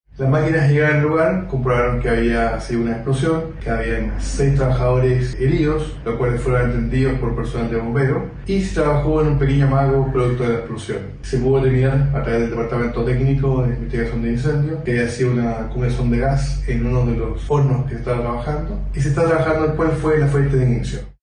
explosion-panaderia.mp3